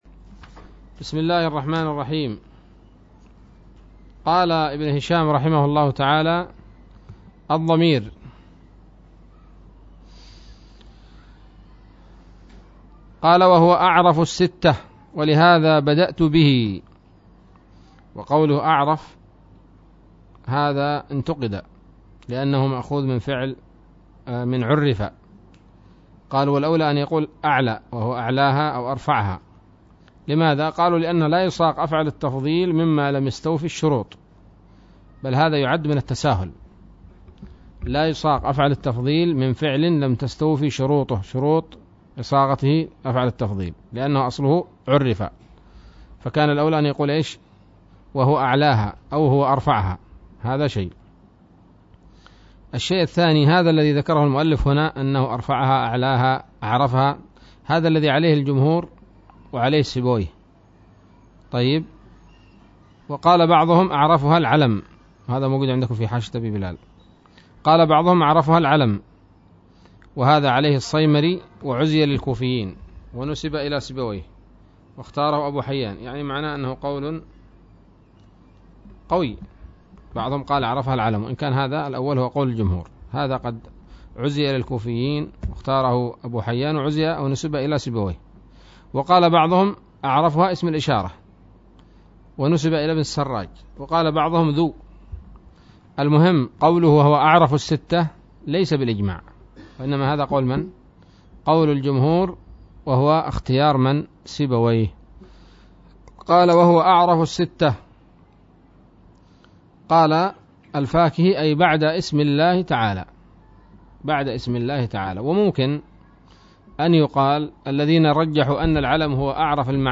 الدرس الحادي والأربعون من شرح قطر الندى وبل الصدى